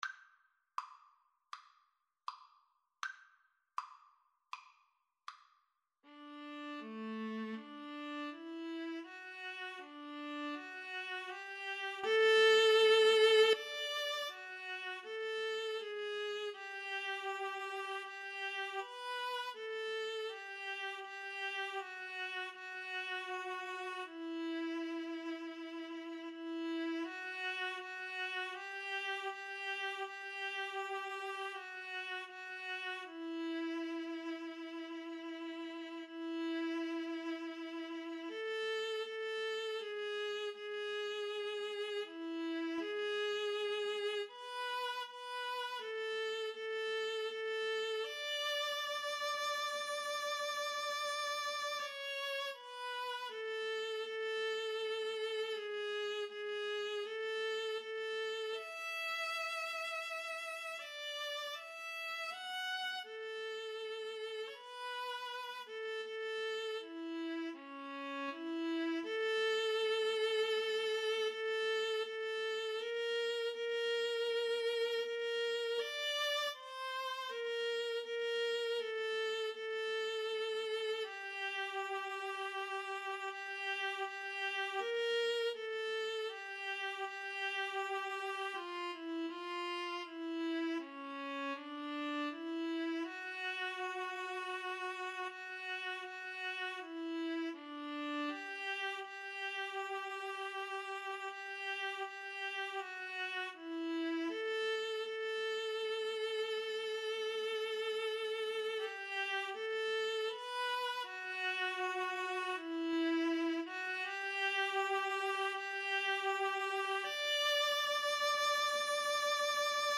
Adagio = c. 40
Classical (View more Classical Viola Duet Music)